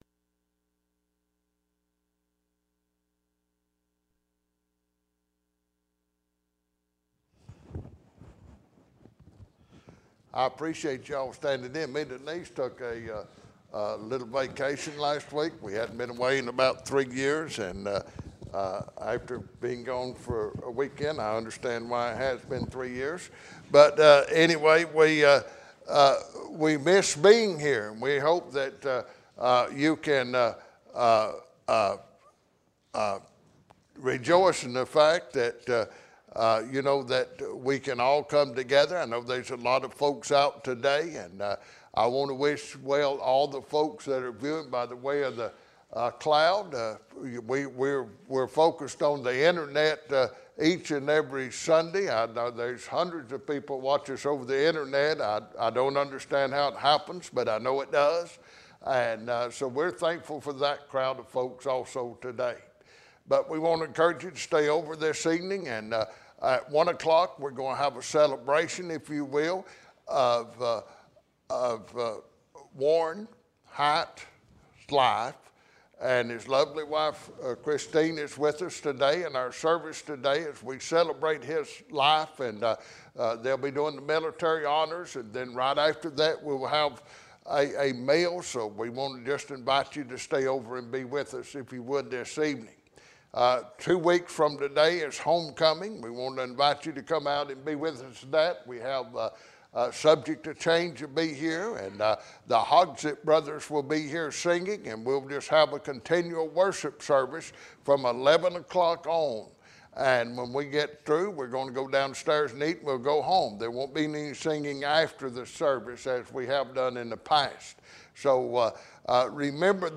How to Live to a Ripe Old Age - Moss Memorial Baptist Church